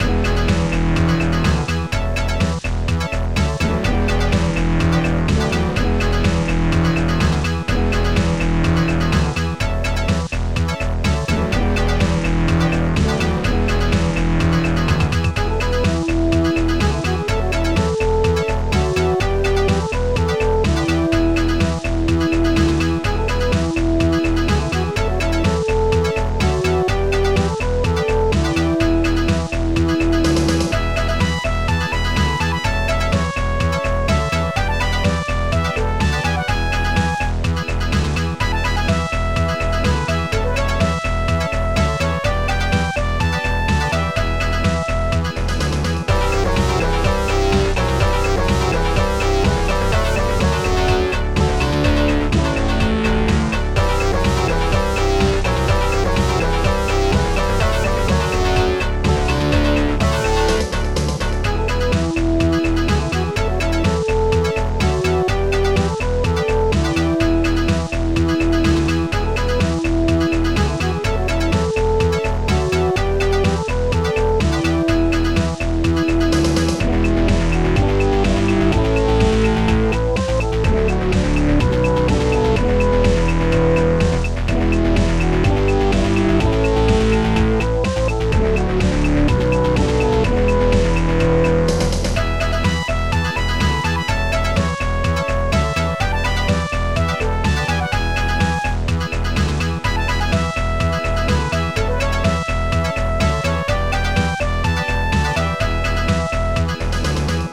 Protracker Module
Instruments st-18:WOW st-18:SOLO1 st-18:Flugelhorn ST-17:wsnare6 st-18:Gove3 st-18:Gove5 ST-17:wethermenbass2 st-18:drum3 st-18:SOLO1